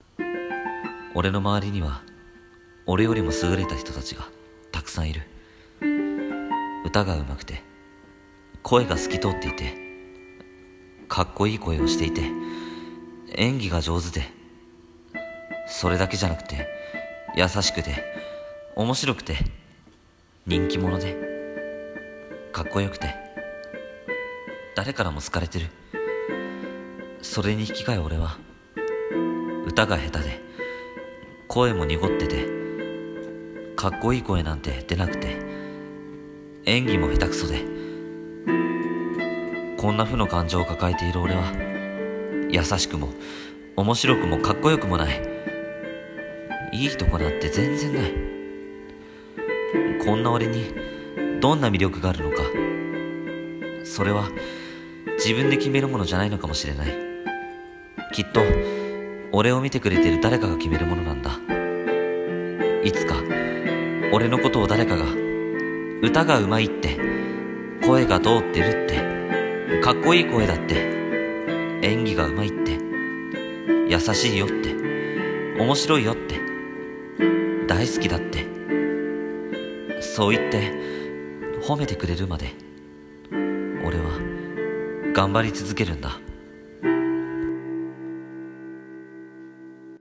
【声劇】自信消失